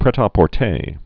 (prĕtä-pôr-tā)